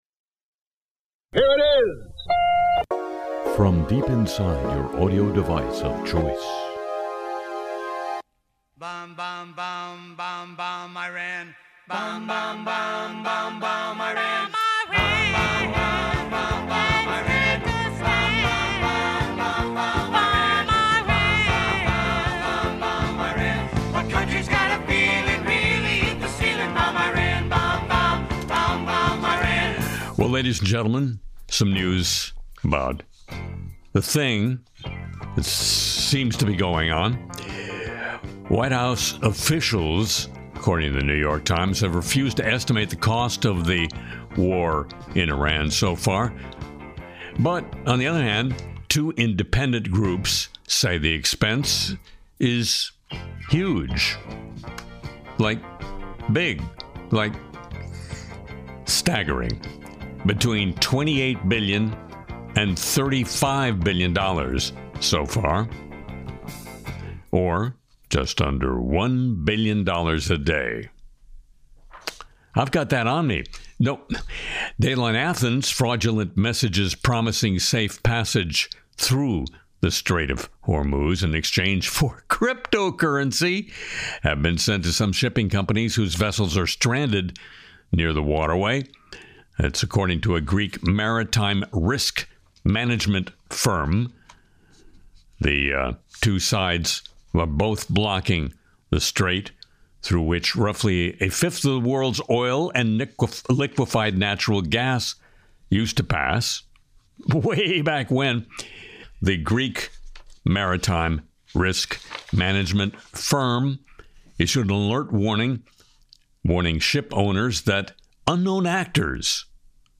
Harry Shearer sings Never Saying Neverland Again, covers Kristi Noem and ASPD, and explores how AI chatbots are feeding delusions.